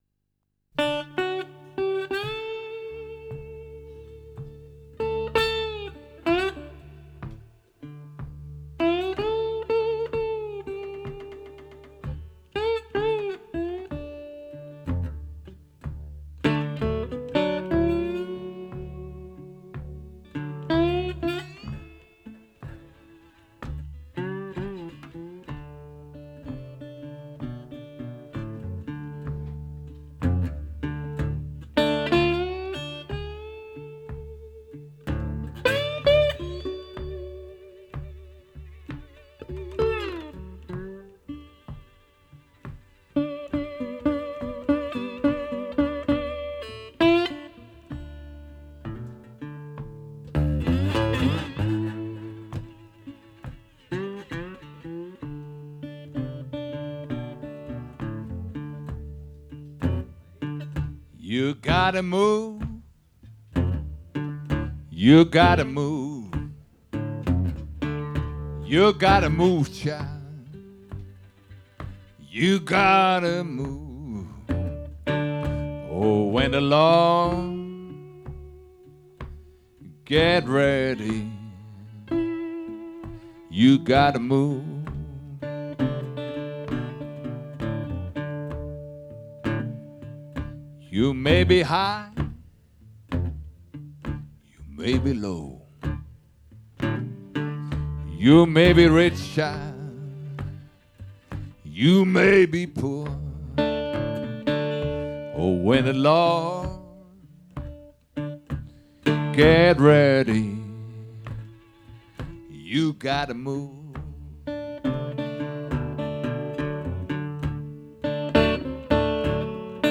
Kann es weich sein, voluminös, federnd, swingend?
im langsamen Swing
Ein Sennheiser MD 412-2 fing das f oot-tapping ein